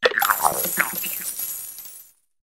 gimmighoul_ambient.ogg